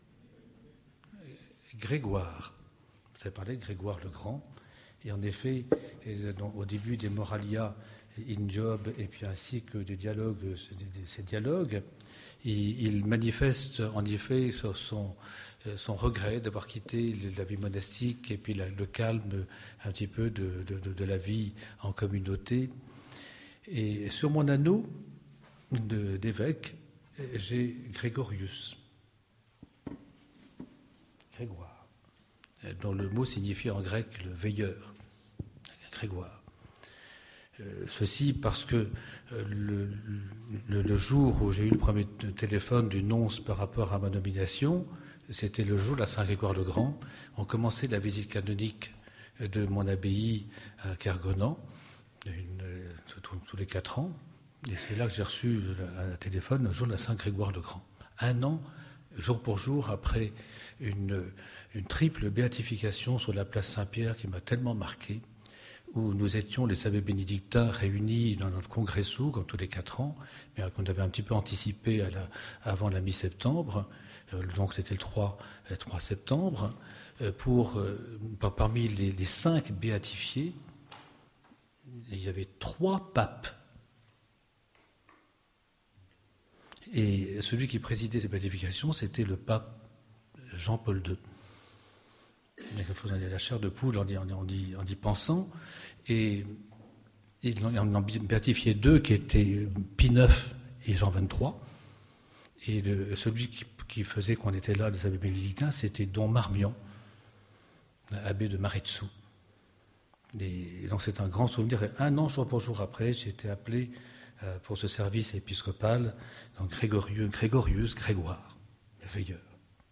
Les mots de la messe - Conférence
Avec la participation de Monseigneur Le Gall